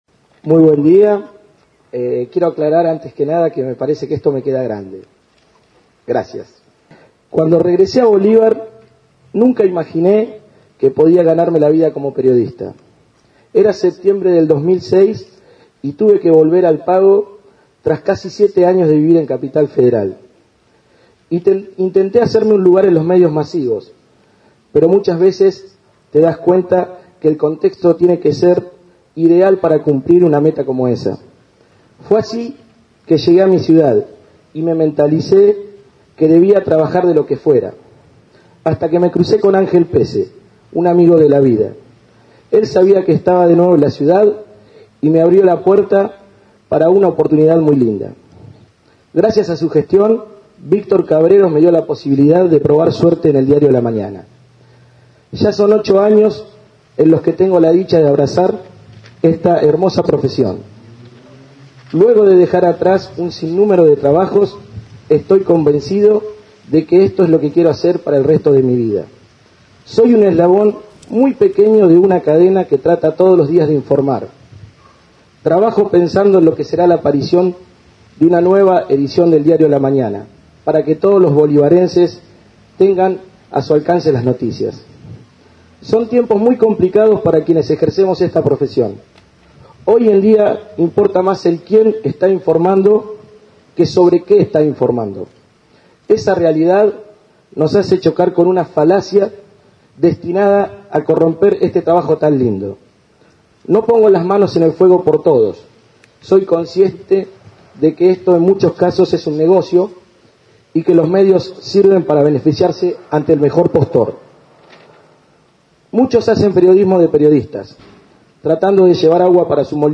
Acto del Día del Periodista en Bolívar - Palabras alusivas